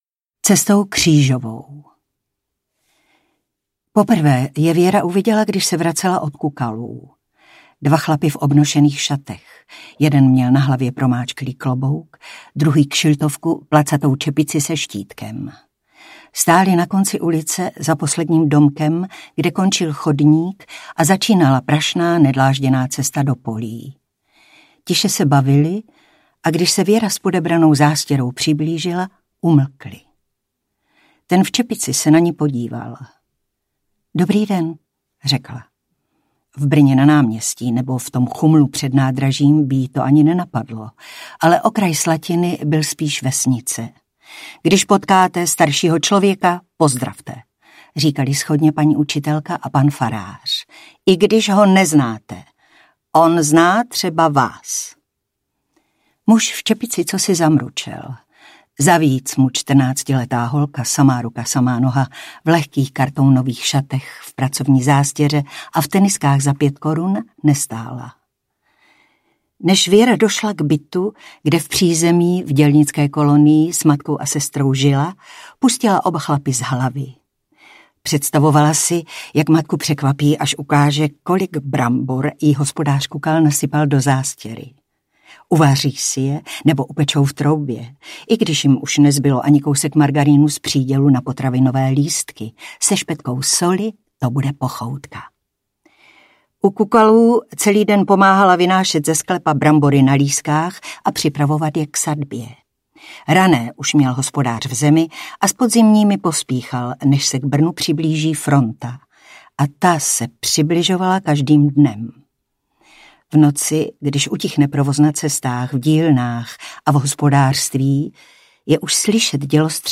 Krvavé jahody audiokniha
Ukázka z knihy